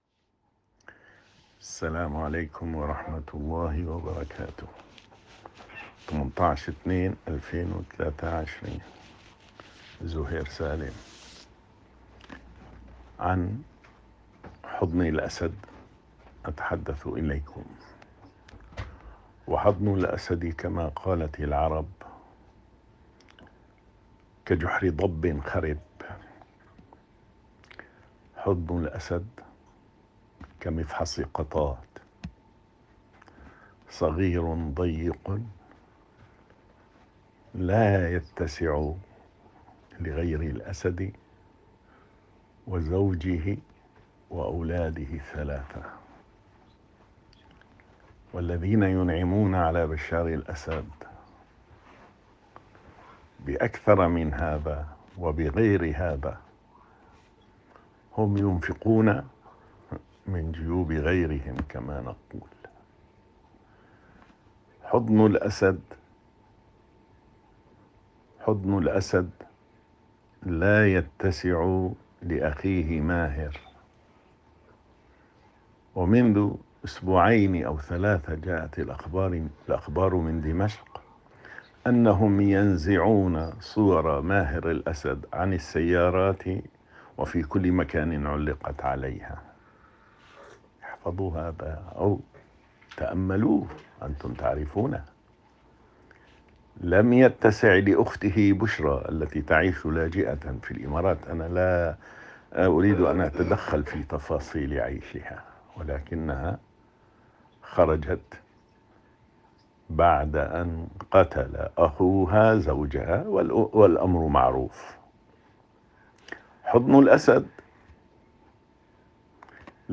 رسالة صوتية :